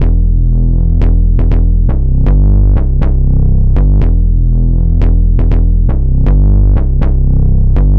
TSNRG2 Bassline 018.wav